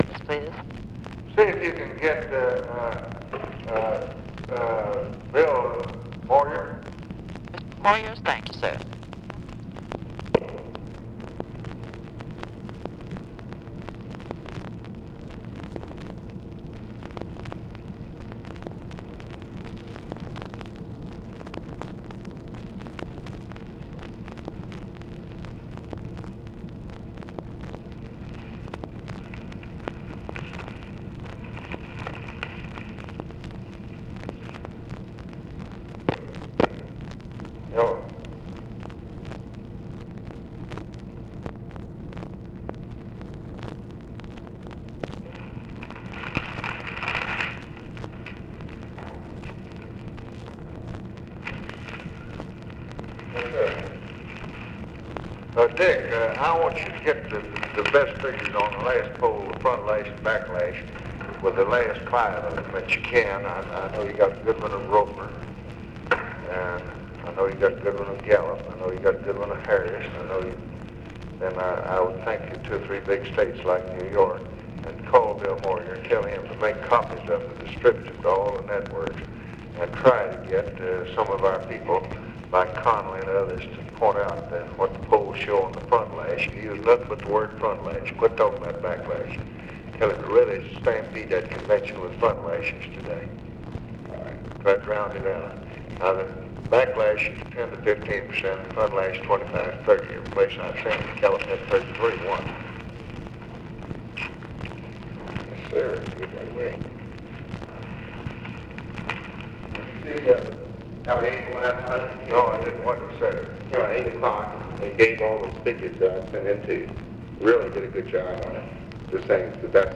Conversation with BILL MOYERS and OFFICE CONVERSATION, August 26, 1964
Secret White House Tapes